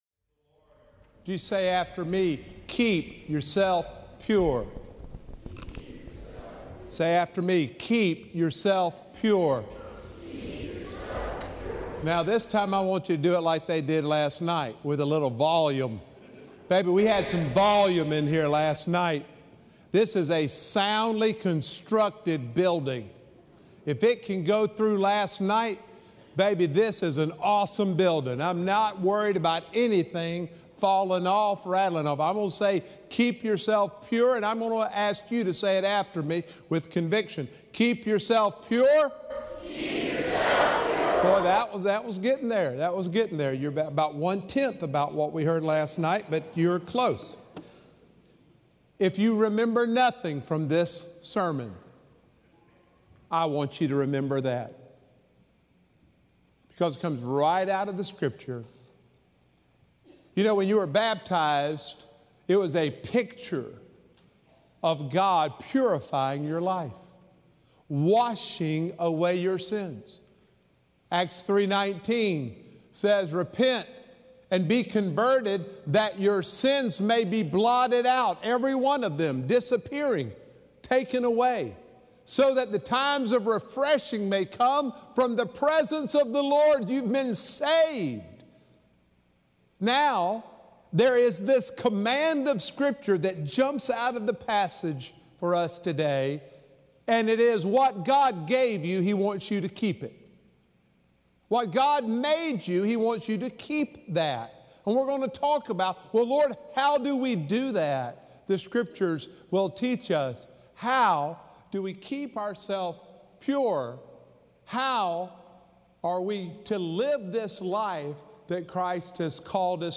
Sermons
February-5-2023-Sermon.mp3